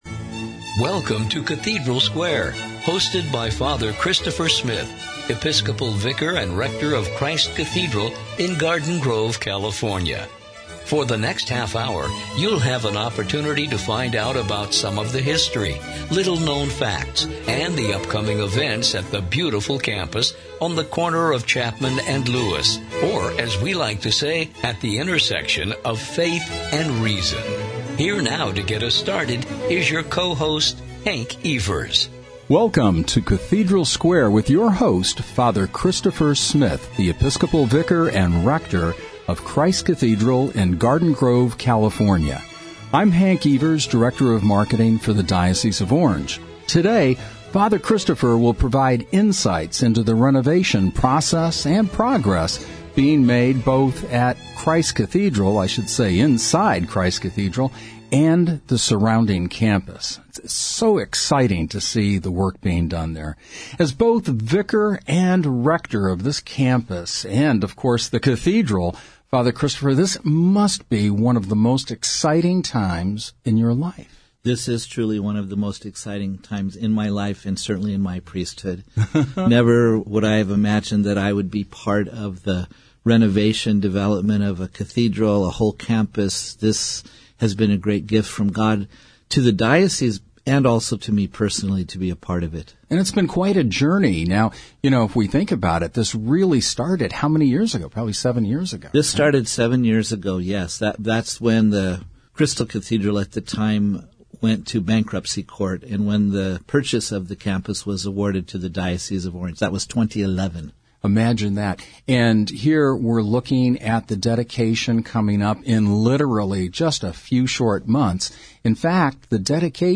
‘virtual audio tour’